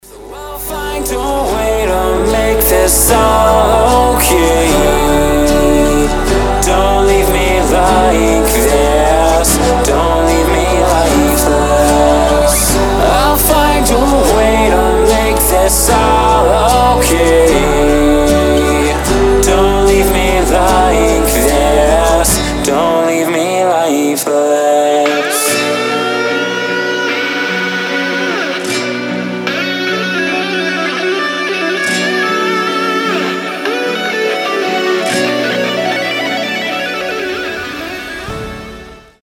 гитара
красивый мужской голос
баллады